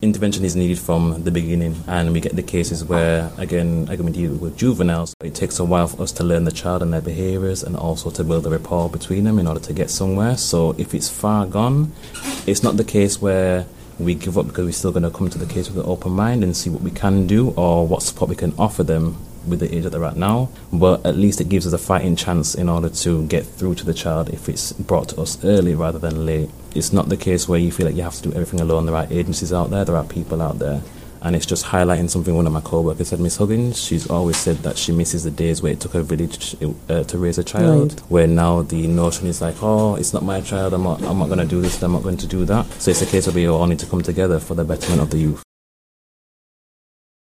Early intervention is key for at risk Youth – A Policing with You Discussion